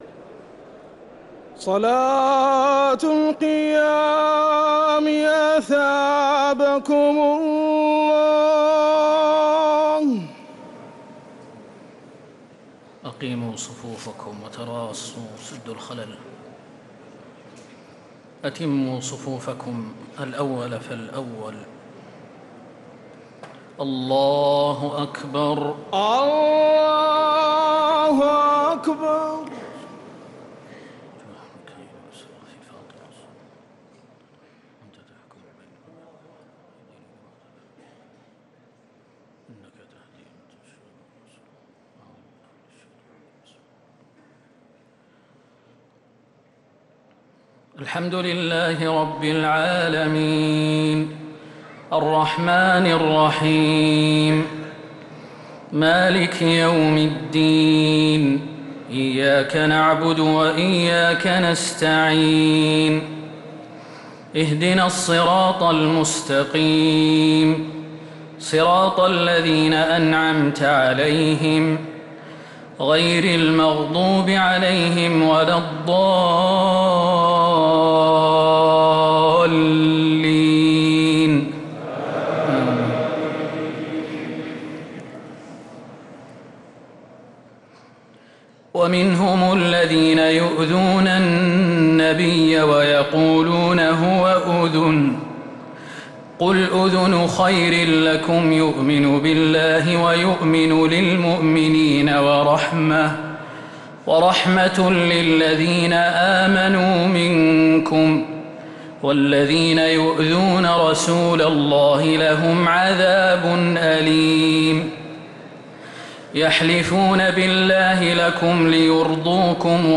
تراويح ليلة 14 رمضان 1446هـ من سورة التوبة (61-116) | taraweeh 14th niqht Surah At-Tawba 1446H > تراويح الحرم النبوي عام 1446 🕌 > التراويح - تلاوات الحرمين